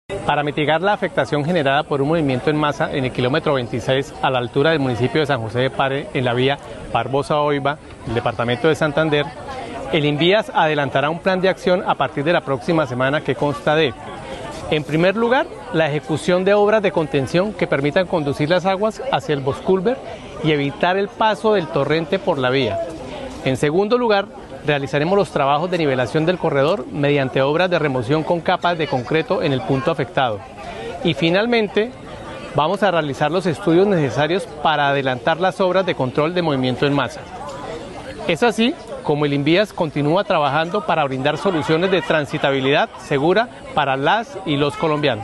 Jhon González, director Invías